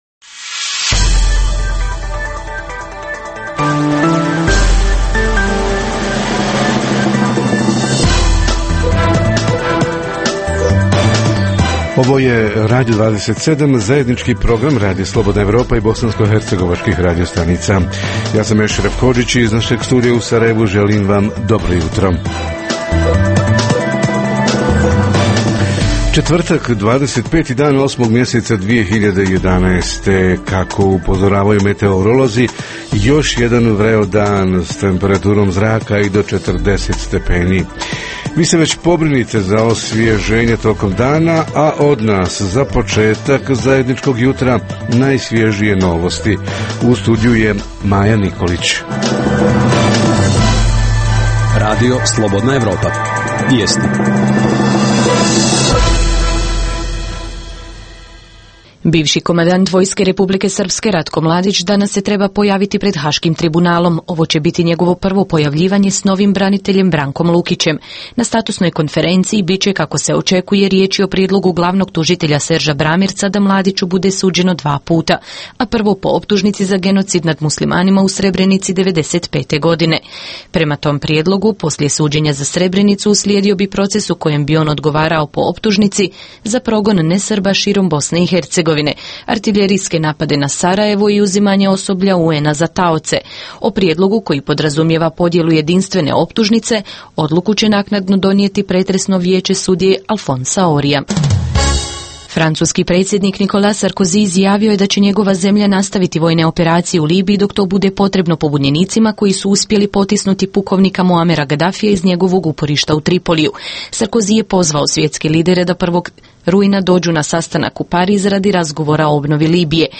Tema jutarnjeg programa: domovi zdravlja - financijsko stanje nakon šest mjeseci ove godine i kvalitet usluga Reporteri iz cijele BiH javljaju o najaktuelnijim događajima u njihovim sredinama.
Redovni sadržaji jutarnjeg programa za BiH su i vijesti i muzika.